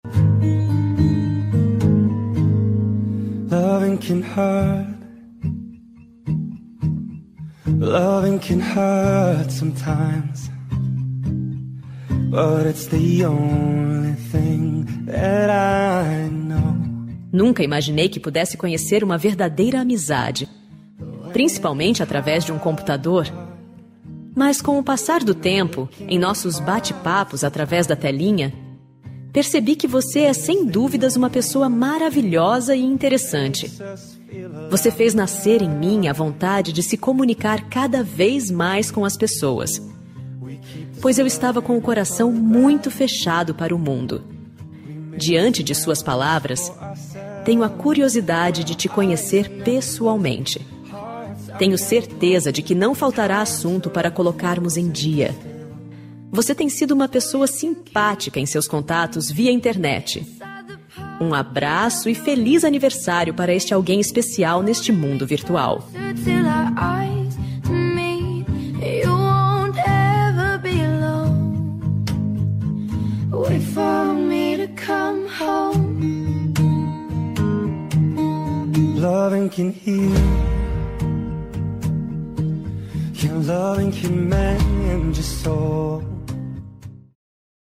Aniversário Virtual Distante – Voz feminina – Cód:8888
aniv-virtual-fem-8888.m4a